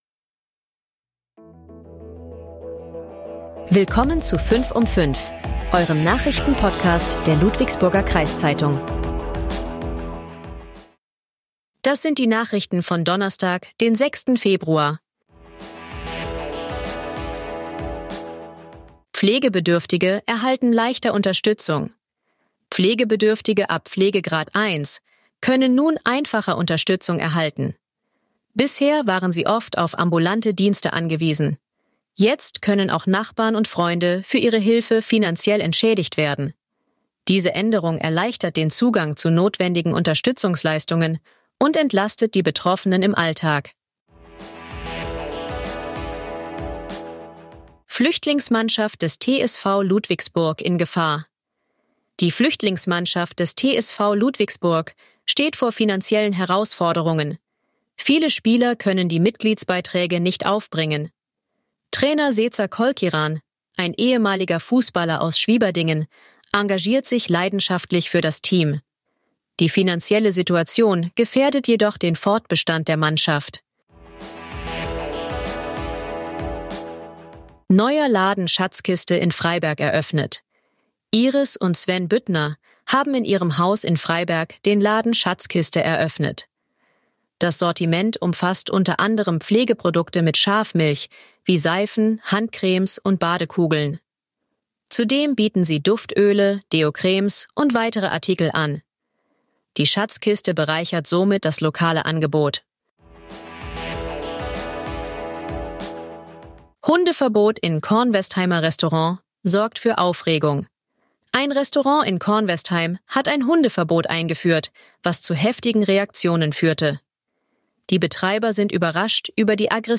Euer Nachrichten-Podcast der Ludwigsburger Kreiszeitung